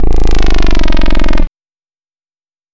Desecrated bass hit 19.wav